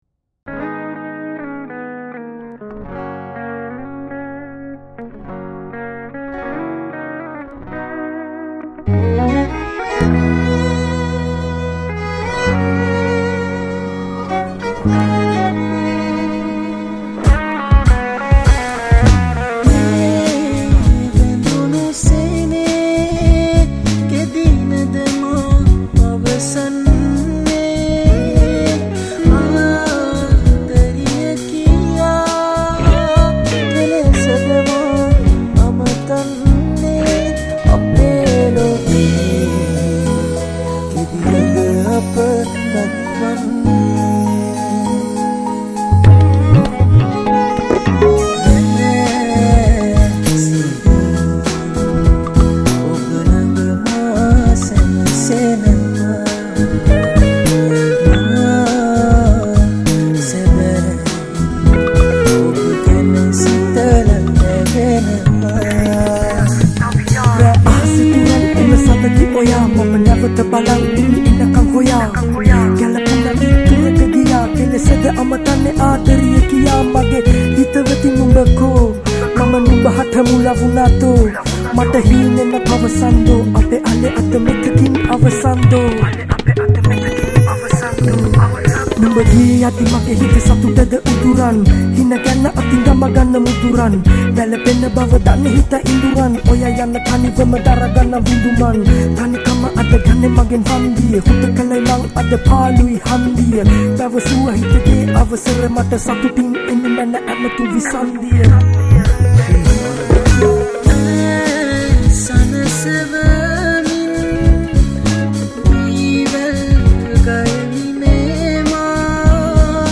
Backing Vocal
Rap